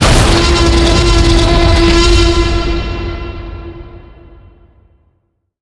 SFX_GoalExplosion_Trex_0001.mp3